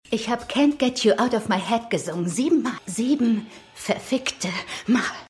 Studio: Interopa Film GmbH [Berlin]